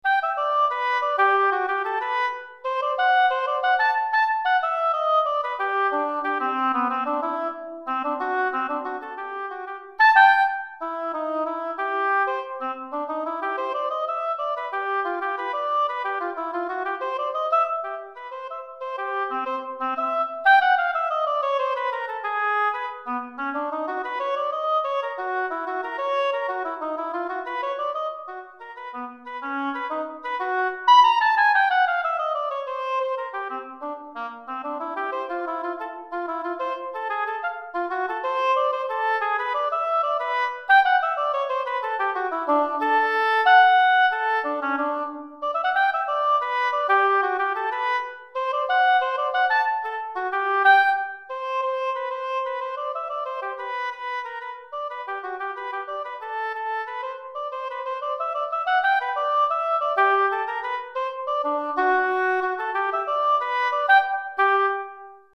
Hautbois Solo